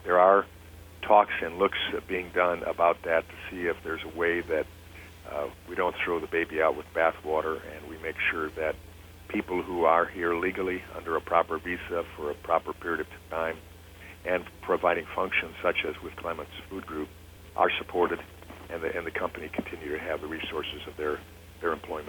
5th District Congressman Tim Walberg says there could be some changes coming to the recent policy shift.
walberg-on-haitian-visas-3-29-25.mp3